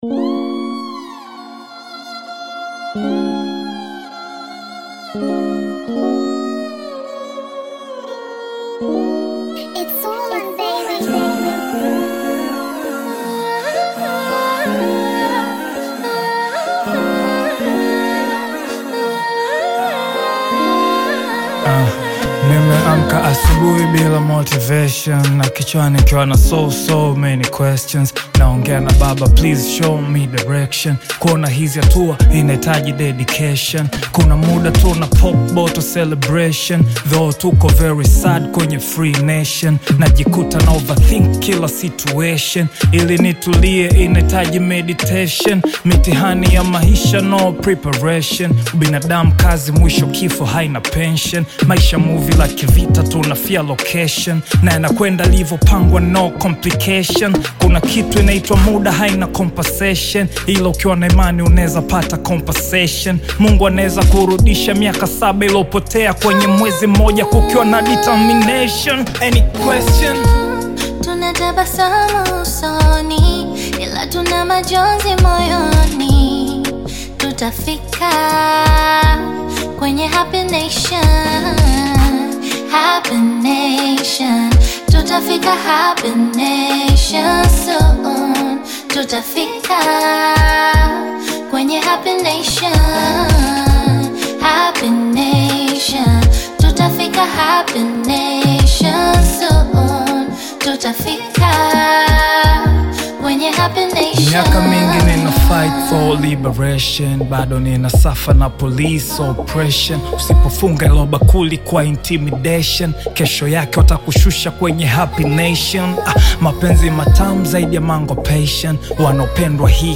On this uplifting anthem
Bongo Flava
fresh vocals and infectious energy